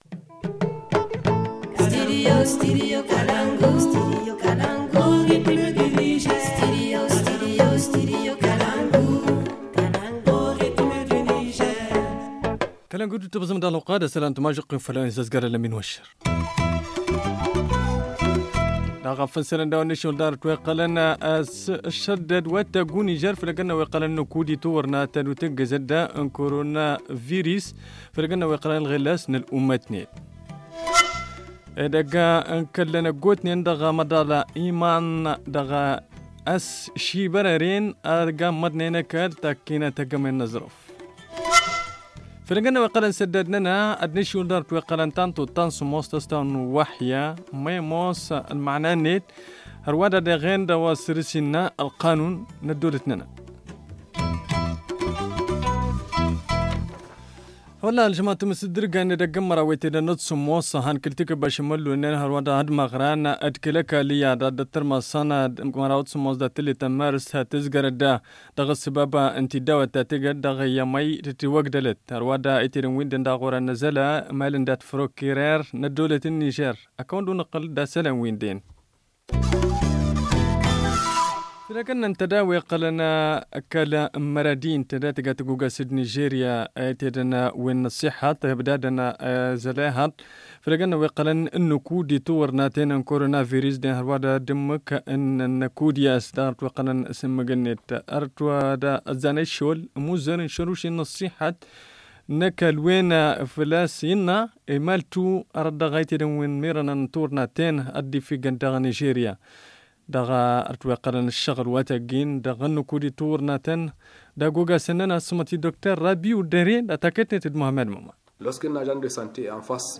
Le journal du 19 mars 2020 - Studio Kalangou - Au rythme du Niger